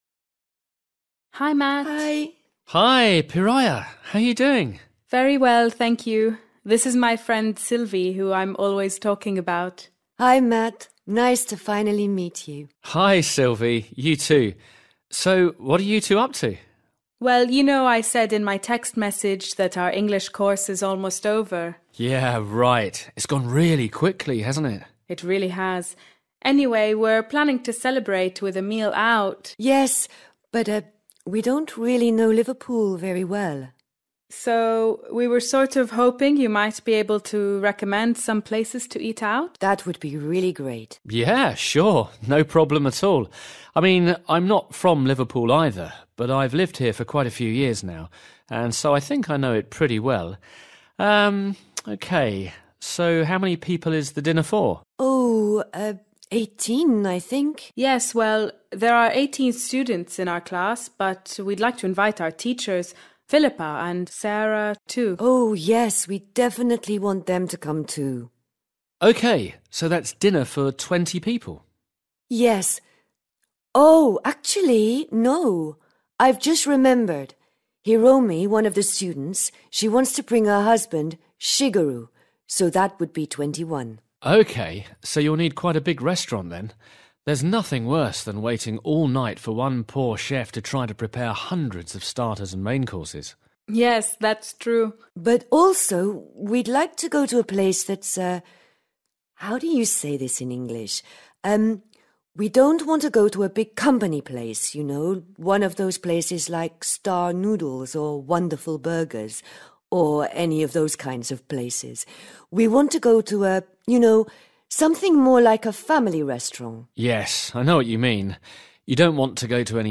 Luyện nghe trình độ B1